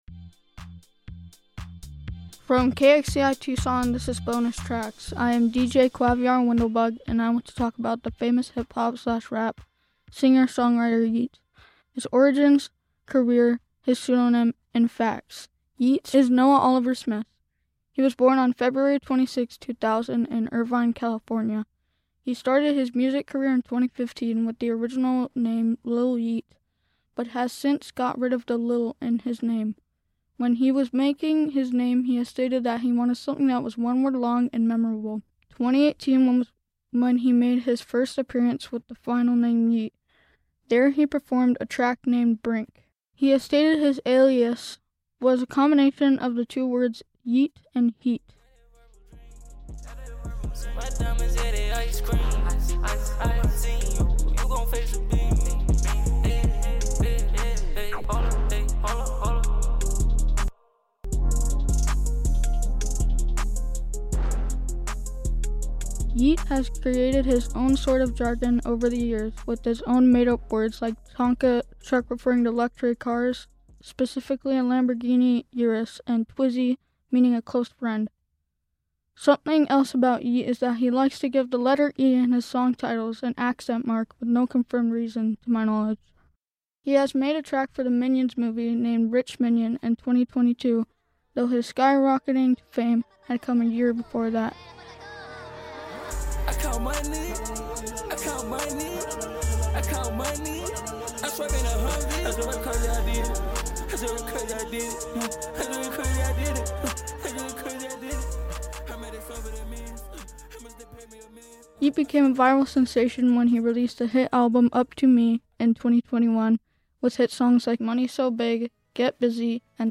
Music Samples for this episode: